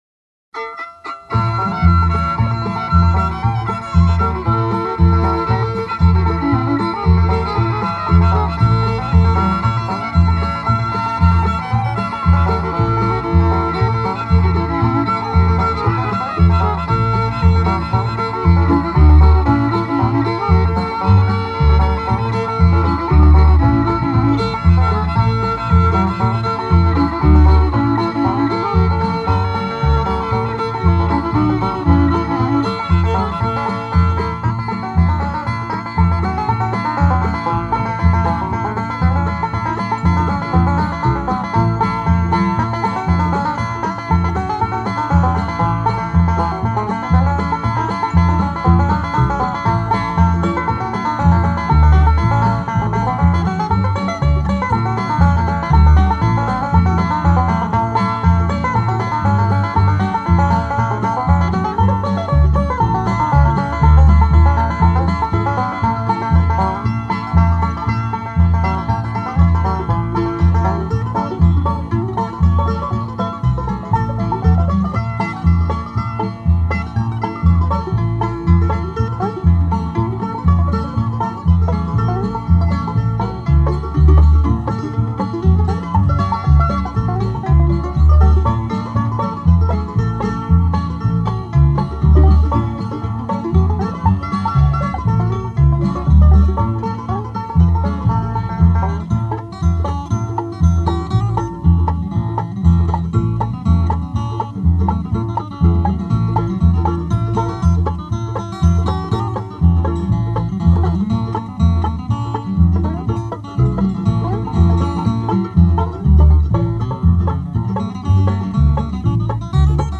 South Plains College  --  Levelland, TX